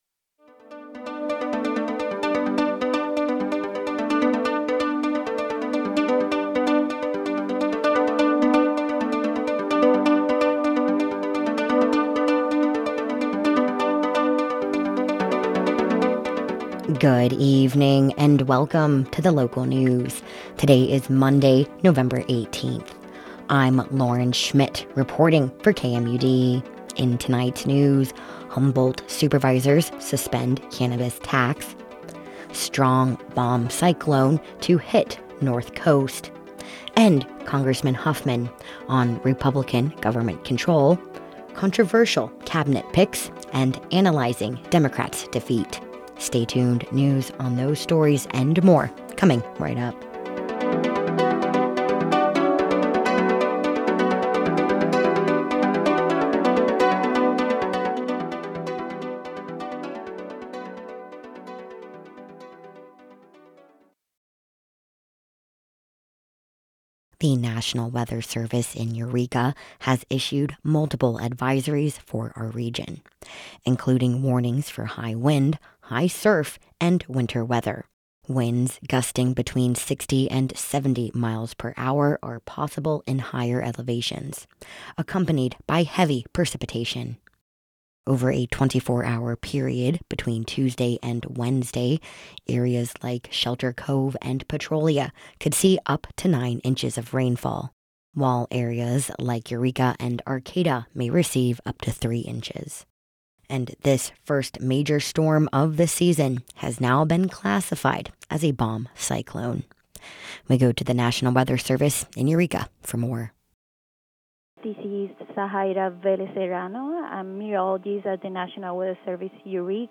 KMUDs Local News report for 11/18/24